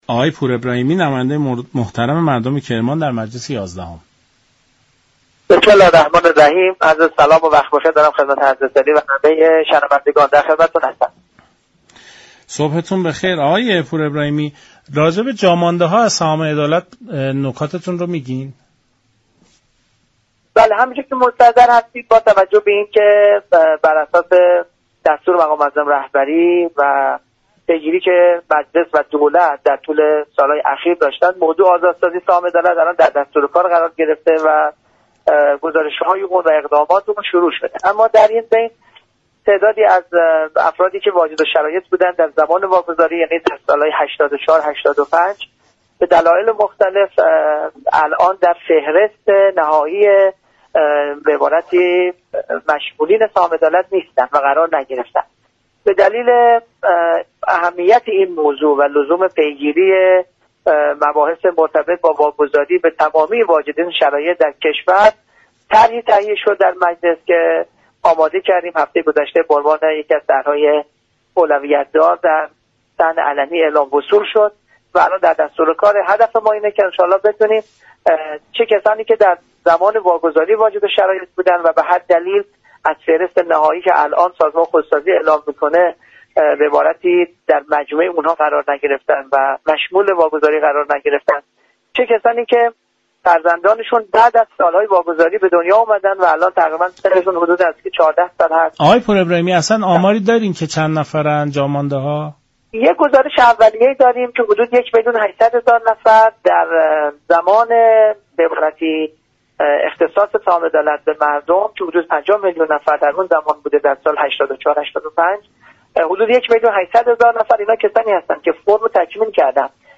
به گزارش شبكه رادیویی ایران، محمدرضا پورابراهیمی نماینده مردم كرمان در مجلس شورای اسلامی در برنامه سلام صبح بخیر درباره وضعیت سهام عدالت گفت: بر اساس دستور مقام معظم رهبری و پیگیری مجلس و دولت، موضوع آزاد سازی سهام عدالت در دستور كار قرار گرفته است.